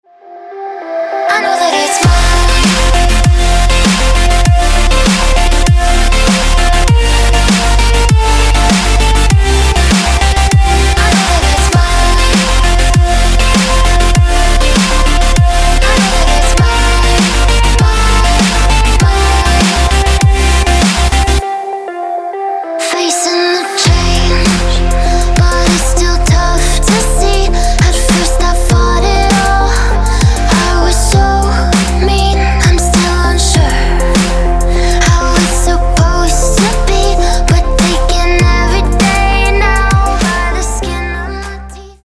• Качество: 192, Stereo
нежный голос
Мелодичный дабстеп